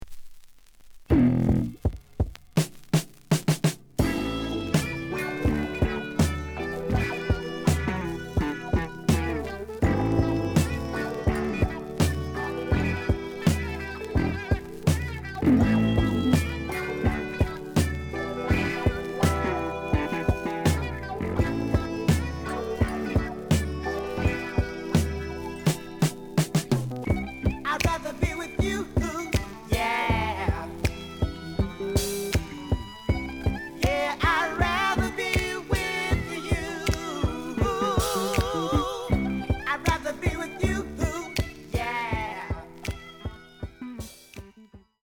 The audio sample is recorded from the actual item.
●Genre: Funk, 80's / 90's Funk
Looks good, but slight noise on both sides.)